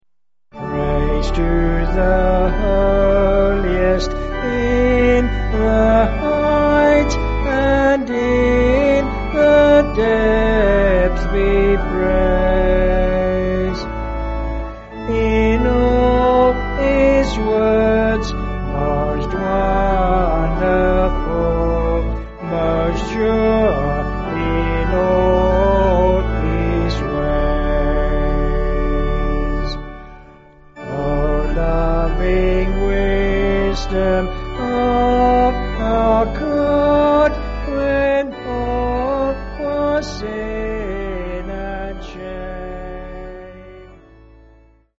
Vocals and Band   263kb Sung Lyrics